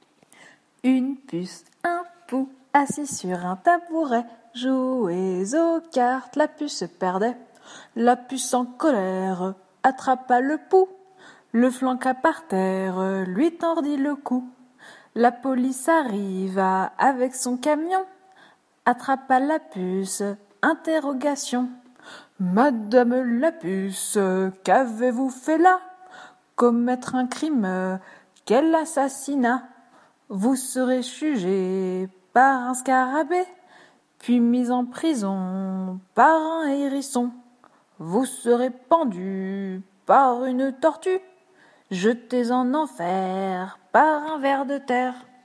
Comptines